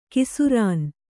♪ kisurān